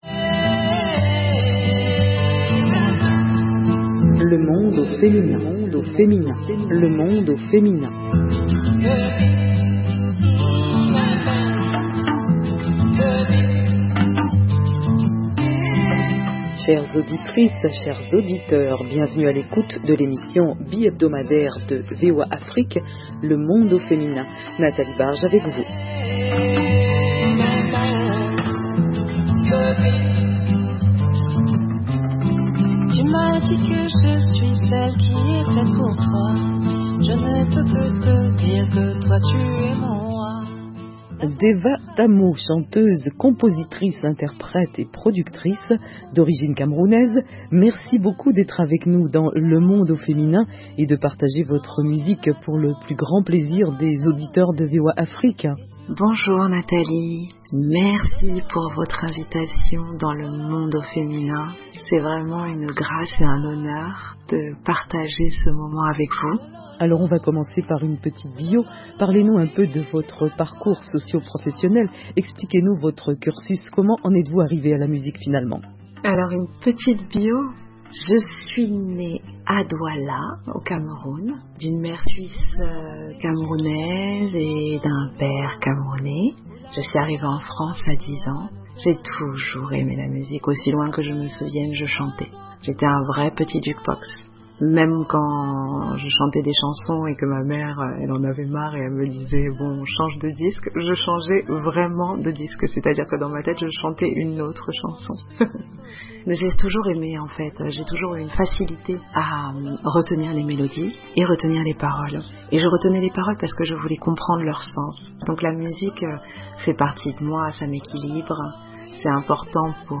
Le Monde au Féminin : Entretien musical